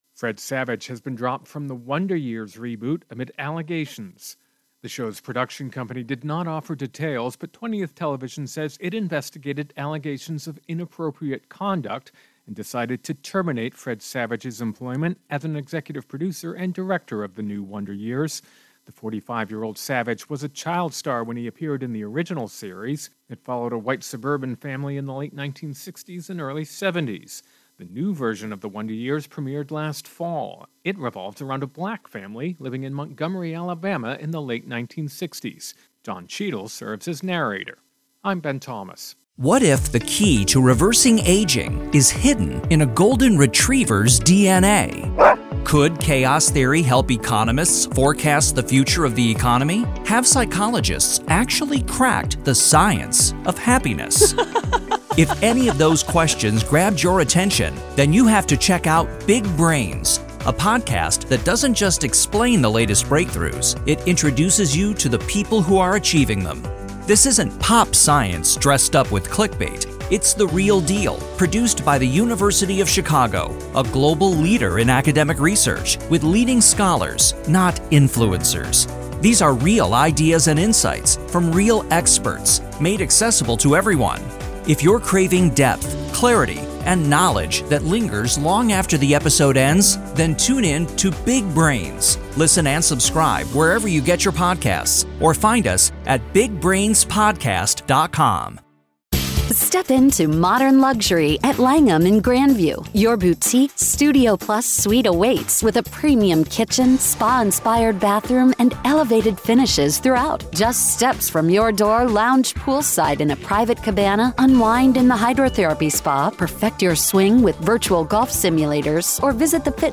Intro and voicer "Fred Savage"